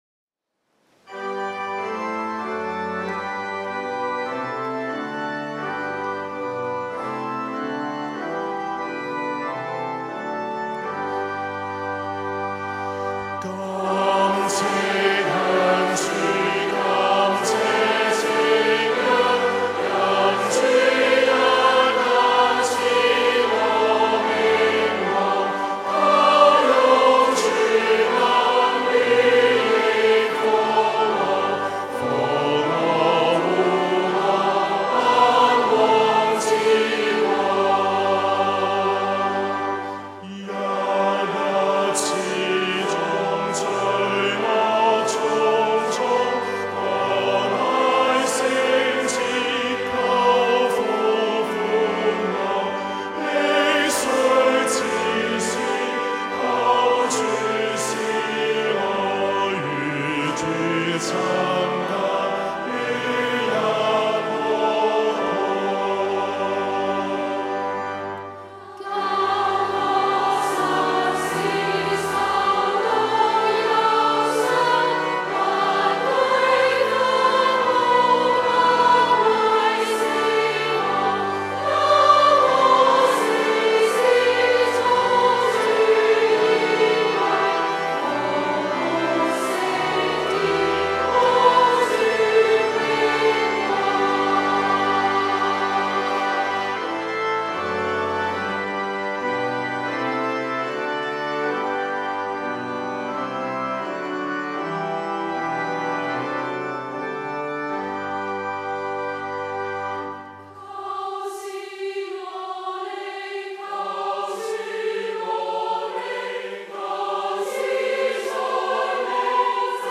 曲：Thomas Ken 1695 調：Tallis’ Canon (Evening Hymn) 格律：長律 Long Metre (8.8.8.8.)